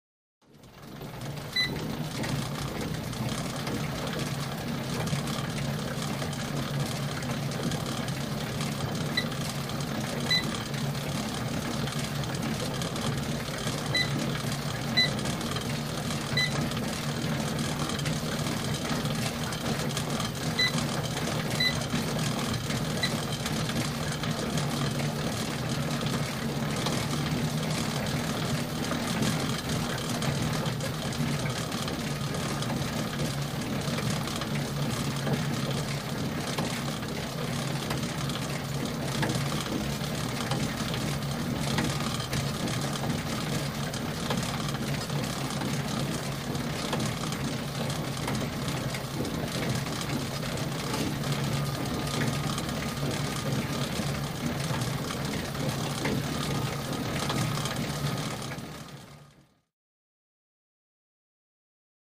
Bike | Sneak On The Lot
Cardiovascular Exercise; Exercise Bike Starts Up with Programming Routine ( Beeps ).